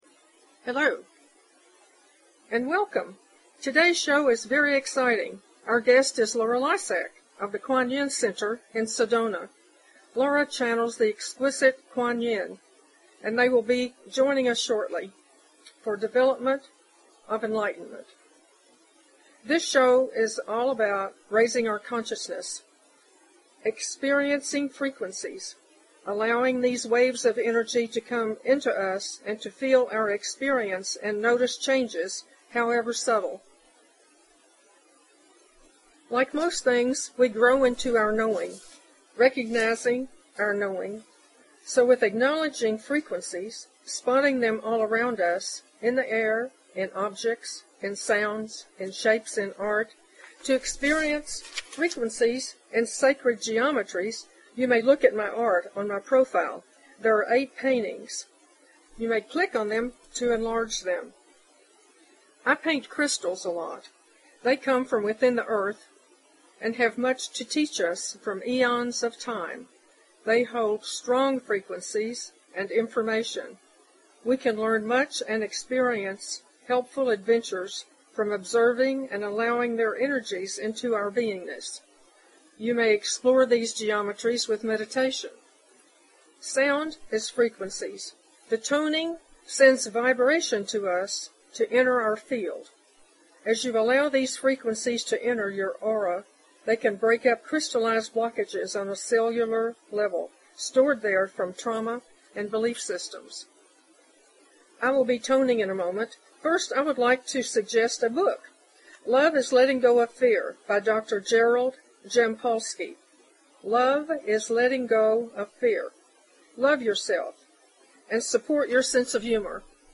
Talk Show Episode, Audio Podcast, Auroras_Frequencies and Courtesy of BBS Radio on , show guests , about , categorized as
These interviews will offer many types of opportunities to broaden and enhance our consciousness and beautify our life experiences.